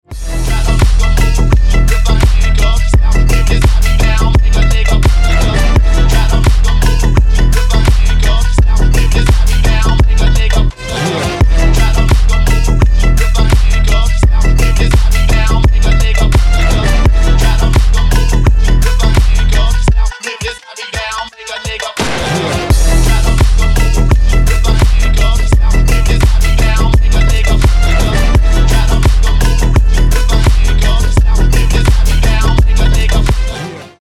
• Качество: 320, Stereo
мощные
восточные